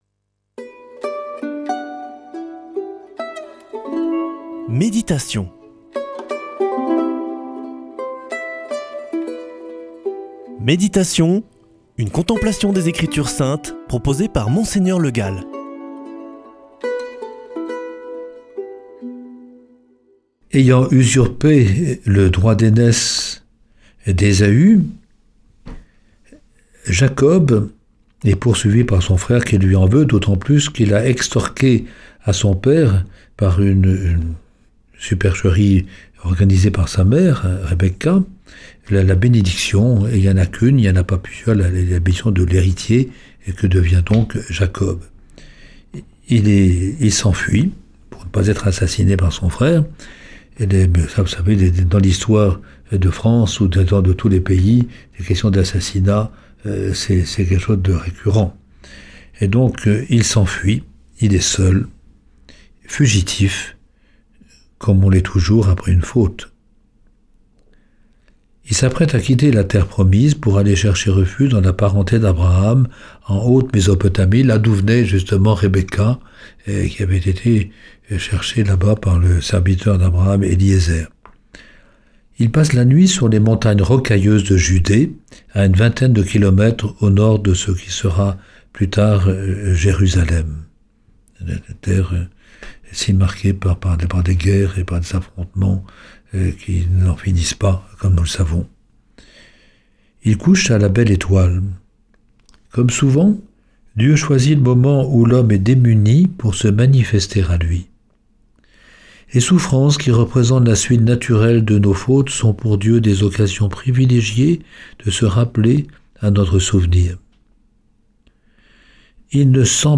Méditation avec Mgr Le Gall
[ Rediffusion ] L’échelle de Jacob